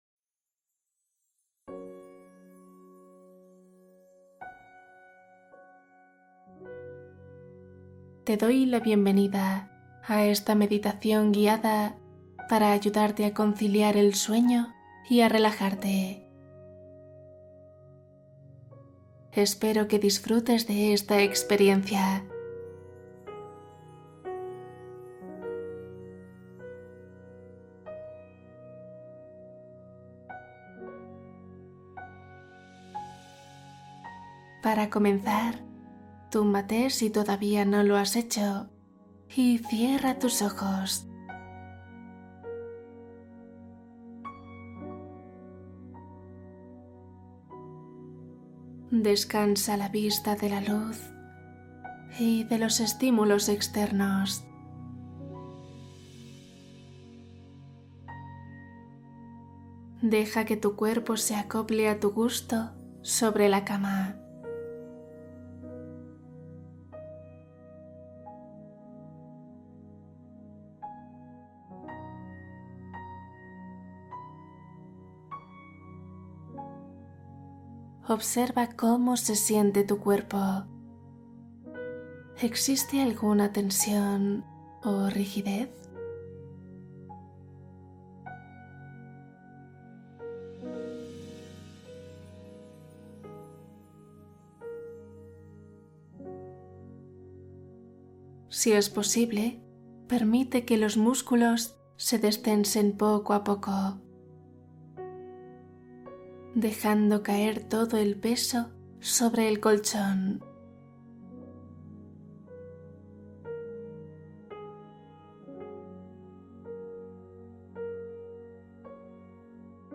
Duerme en minutos Meditación para sueño profundo inmediato